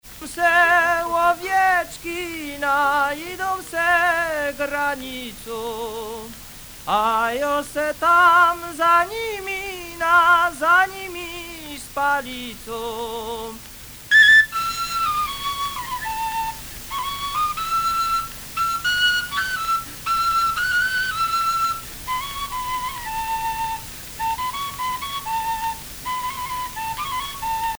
Enquête Lacito-CNRS
Pièce musicale inédite